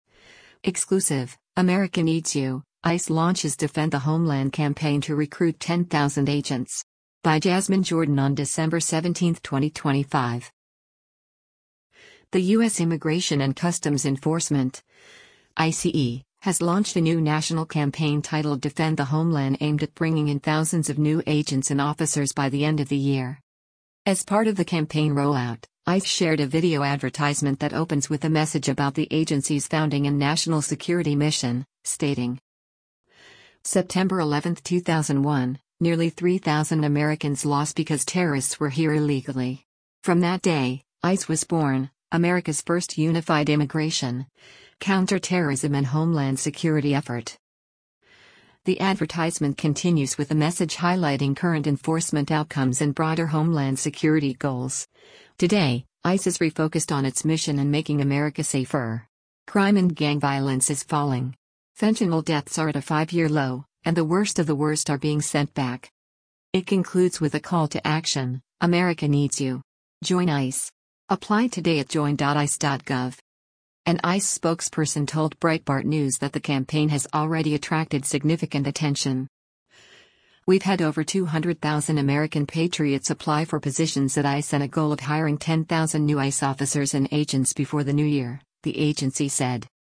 As part of the campaign rollout, ICE shared a video advertisement that opens with a message about the agency’s founding and national security mission, stating: